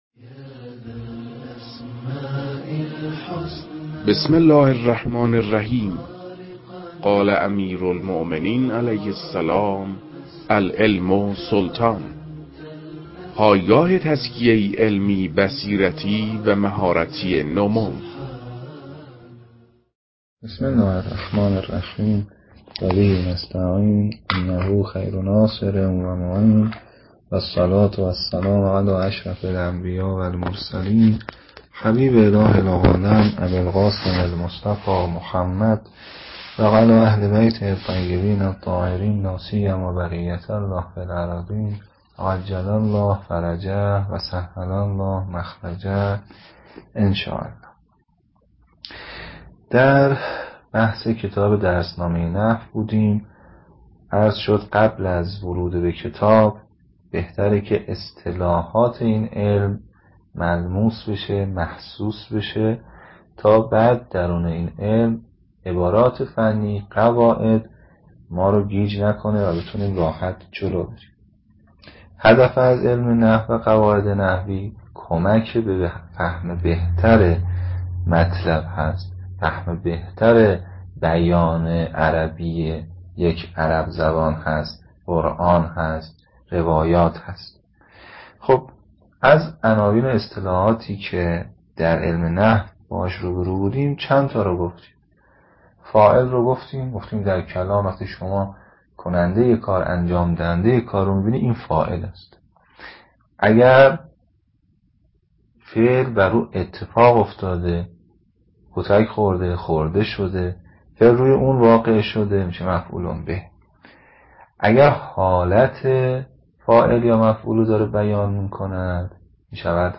در این بخش، کتاب «درسنامه نحو» که اولین کتاب در مرحلۀ آشنایی با علم نحو است، به ترتیب مباحث کتاب، تدریس می‌شود.